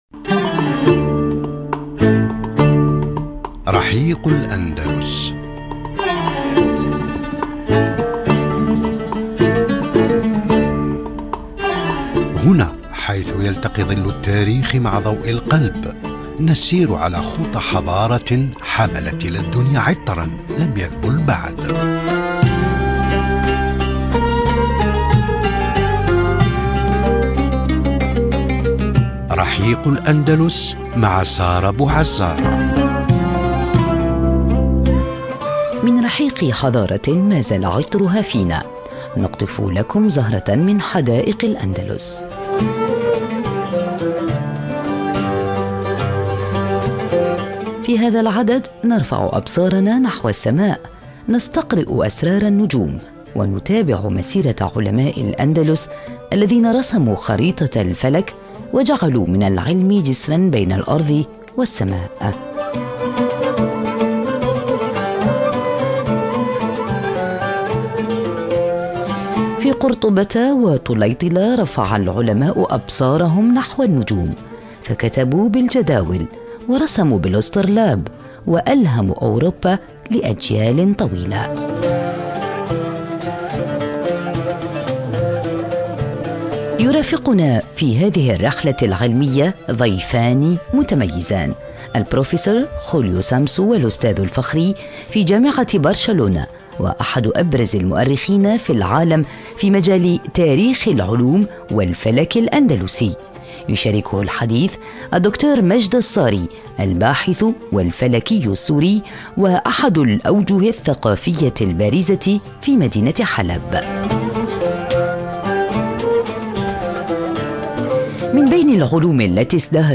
في حوارٍ يجمع بين دقّة العلم وجمال التراث.